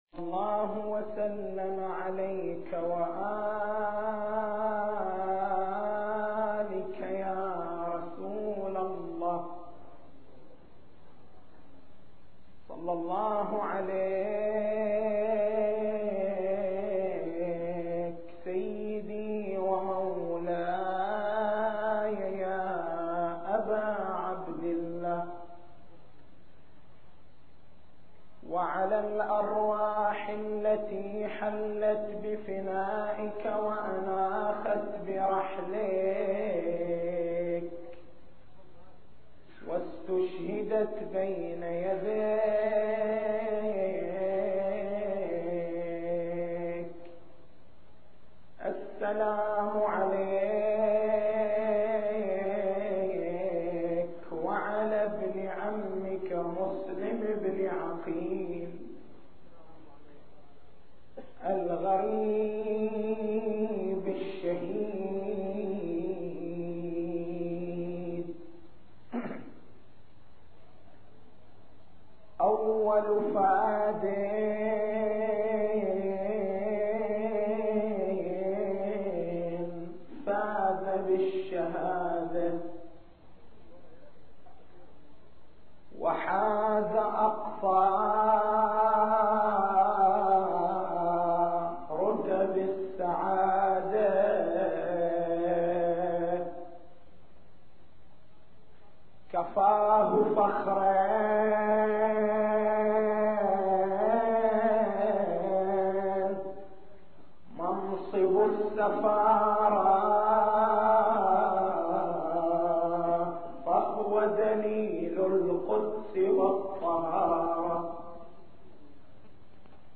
تاريخ المحاضرة